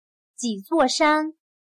几座山/Jǐ zuò shān/Varias montañas